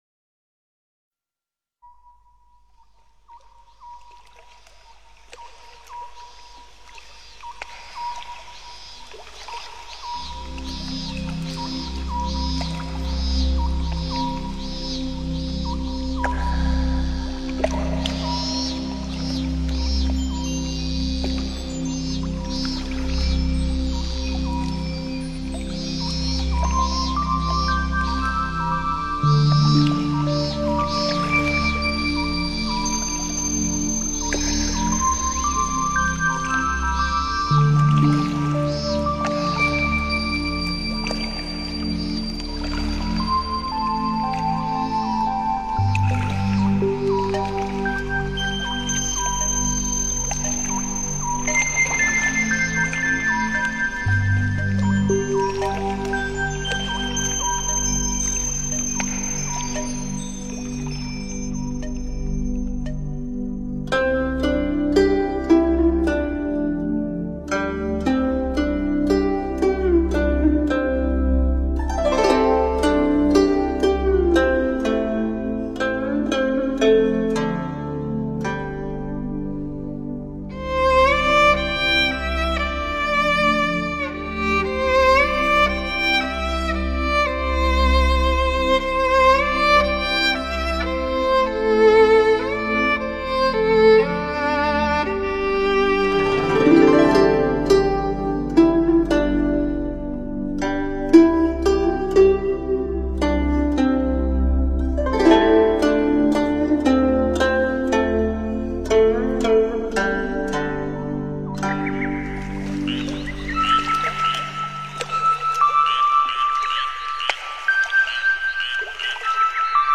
空山静水--佚名 冥想 空山静水--佚名 点我： 标签: 佛音 冥想 佛教音乐 返回列表 上一篇： 105.如何解脱生老病死--佚名 下一篇： 古琴佛曲--佚名 相关文章 优雅恬静--瑜伽 优雅恬静--瑜伽...